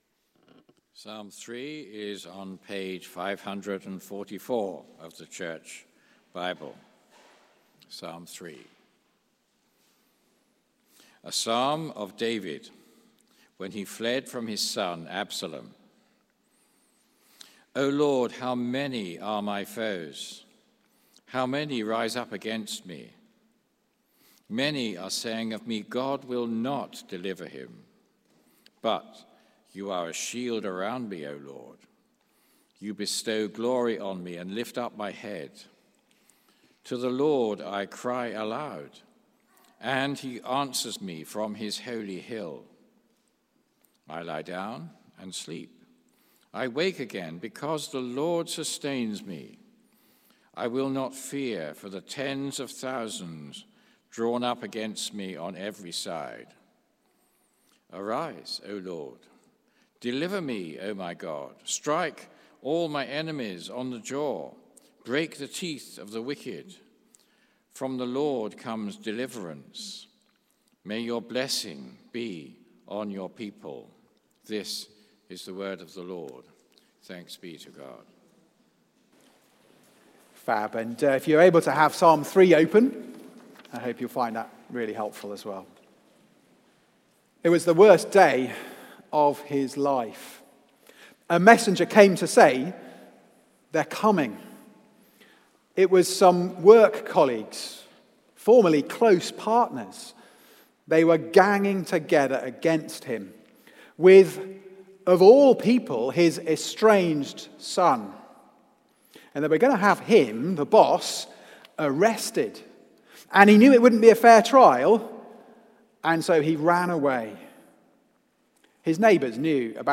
Series: Psalms Book One: The Prayers of God's King Theme: The King who Prayers under Pressure Sermon